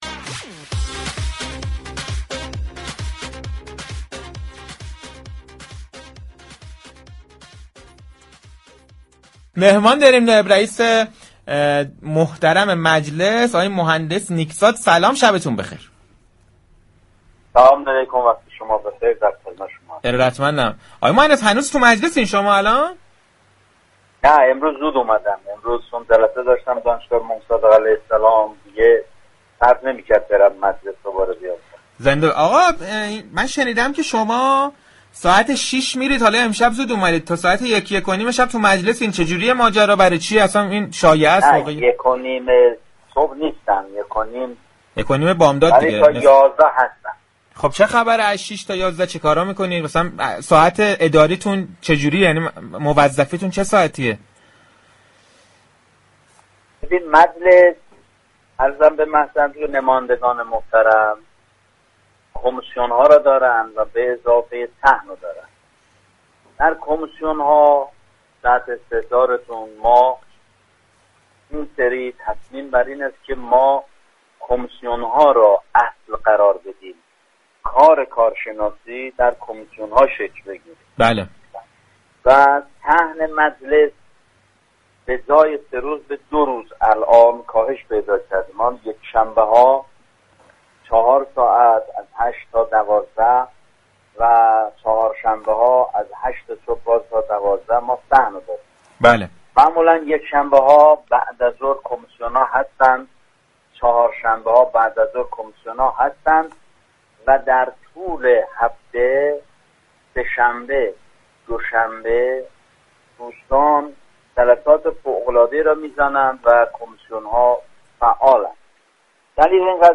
مهندس علی نیكزاد، عضو هیأت رئیسه مجلس شورای اسلامی در گفتگو با برنامه صحنه درباره مراسم عزاداری محرم، تبدیل نفت به قیر، زمان برگزاری كنكور و طرح مالیات بر خانه های خالی گفتگو كرد.